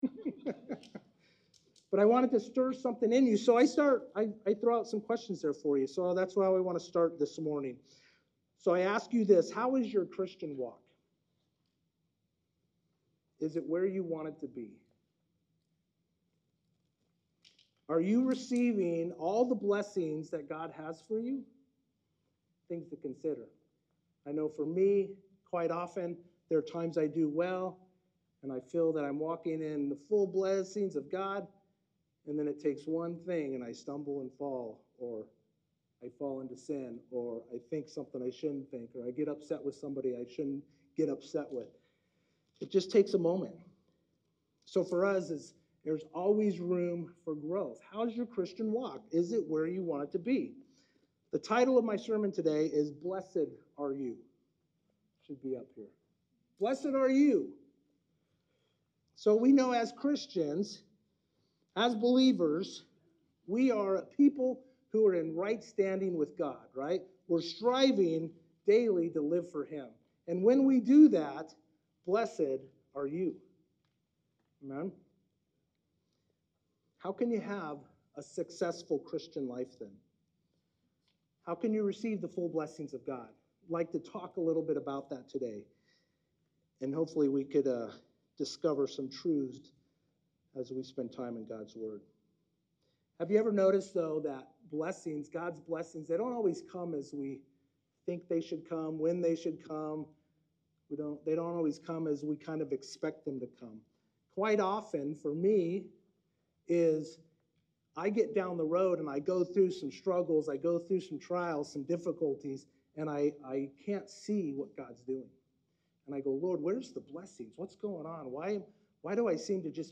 This Sunday at LifeHouse Church